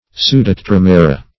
Search Result for " pseudotetramera" : The Collaborative International Dictionary of English v.0.48: Pseudotetramera \Pseu`do*te*tram"e*ra\, n. pl.